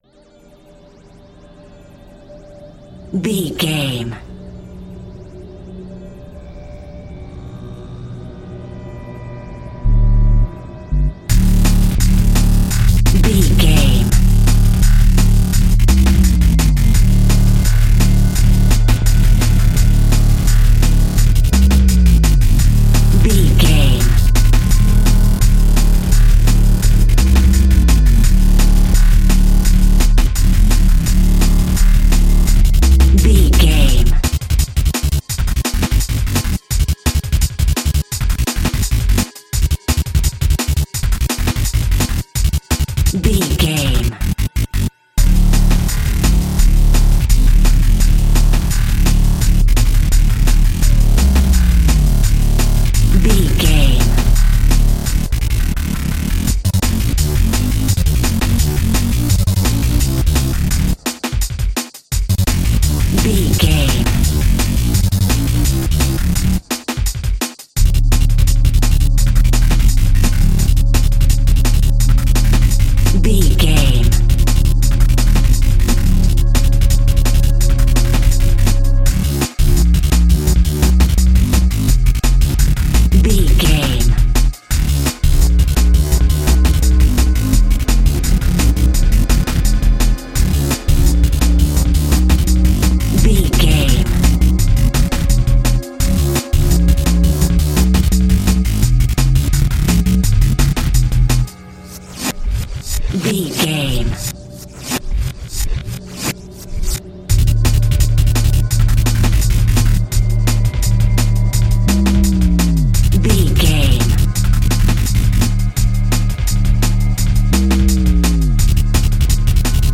Aeolian/Minor
synthesiser
electric guitar
drum machine